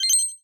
Coins (8).wav